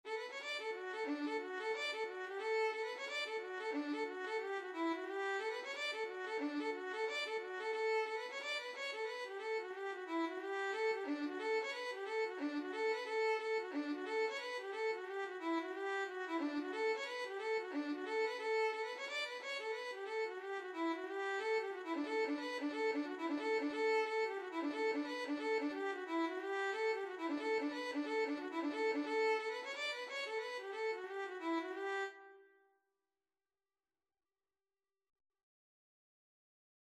Violin version
4/4 (View more 4/4 Music)
D5-D6
Violin  (View more Intermediate Violin Music)
Traditional (View more Traditional Violin Music)